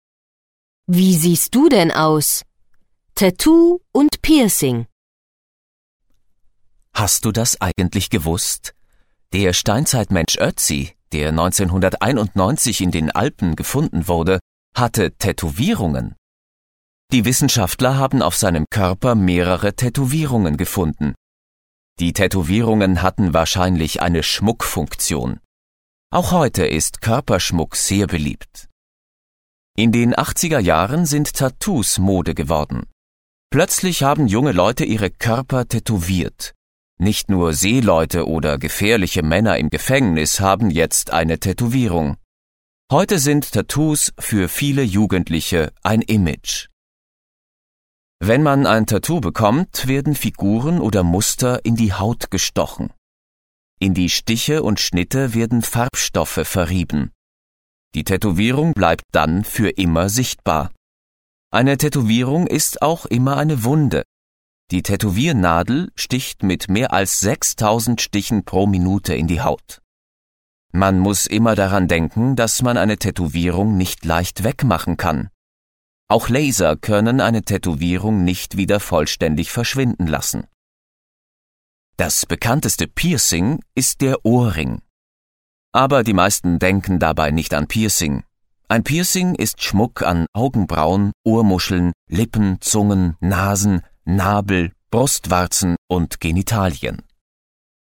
Lytteprøve